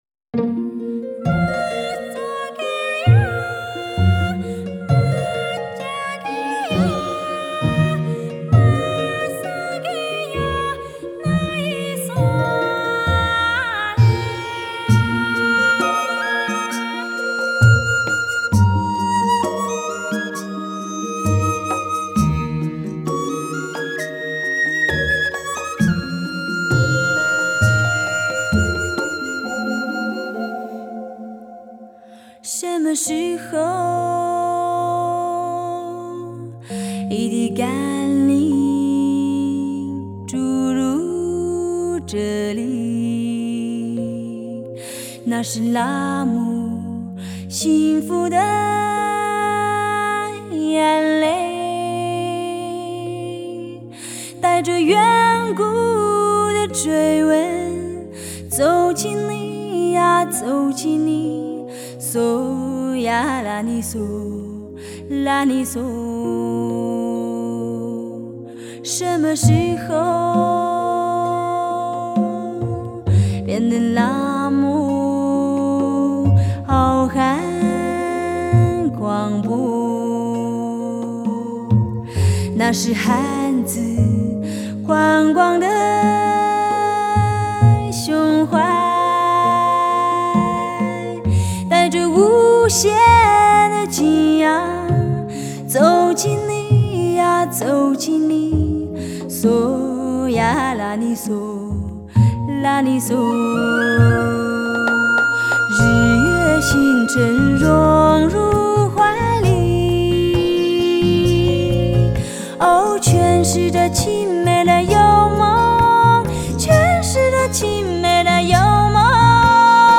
圆润、亮丽，原生态味道浓郁
沧桑悠远，具有直冲云霄的穿透力